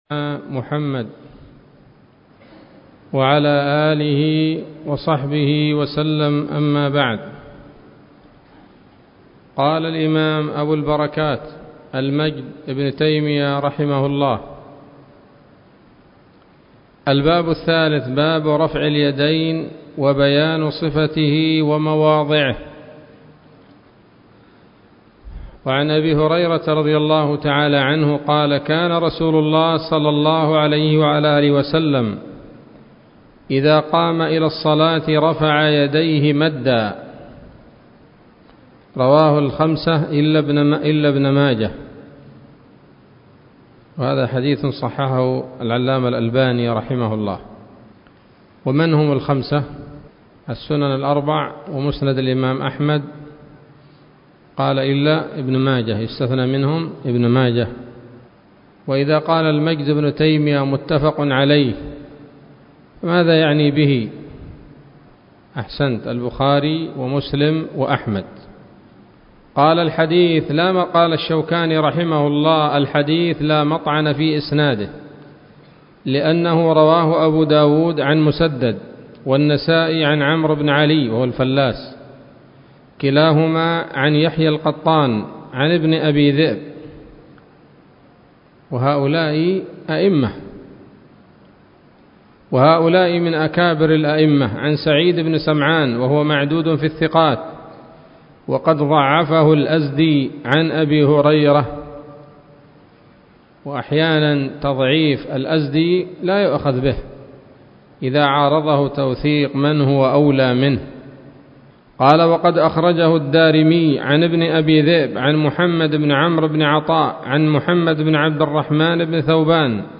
الدرس الرابع من أبواب صفة الصلاة من نيل الأوطار